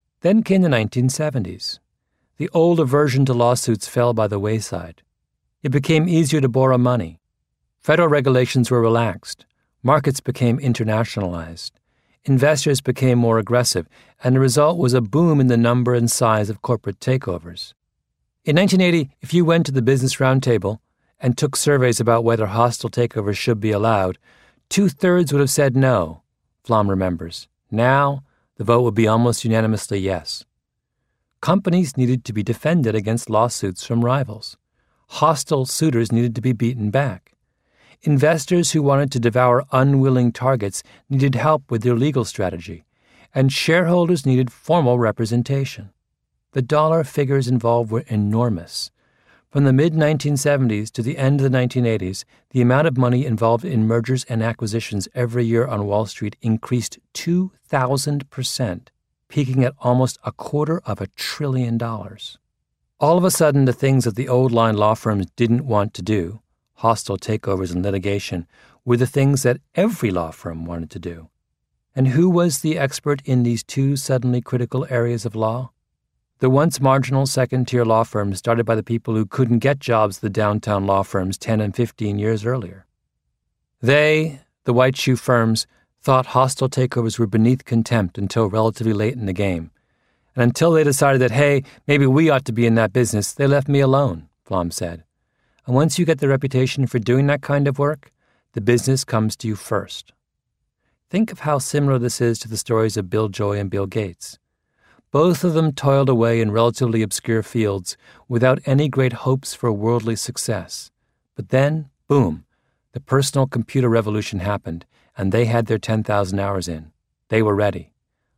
在线英语听力室异类之不一样的成功启示录 第84期:时间会证明你的选择的听力文件下载, 《异类:不一样的成功启示录Outliers:The Story of Success》是外文名著，是双语有声读物下面的子栏目，栏目包含中英字幕以及地道的英语音频朗读文件MP3，通过学习本栏目，英语爱好者可以懂得不一样的成功启示，并在潜移默化中挖掘自身的潜力。